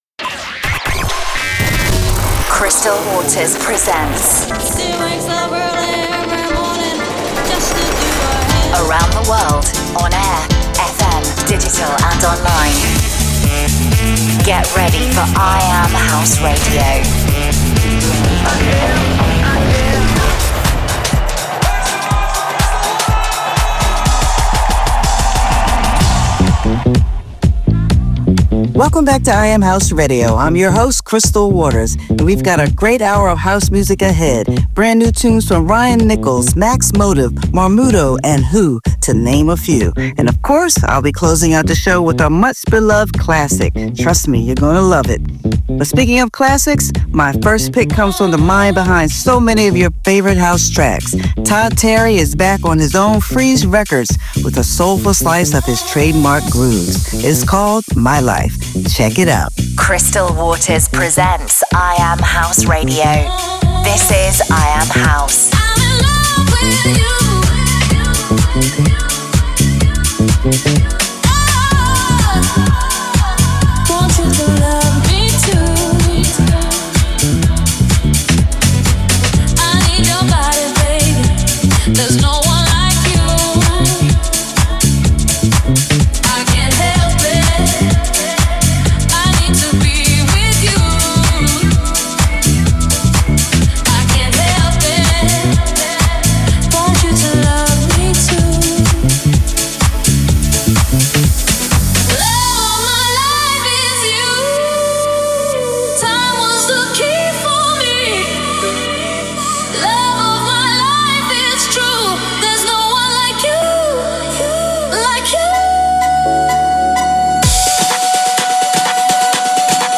Playing the best new House Music from around the world. 01.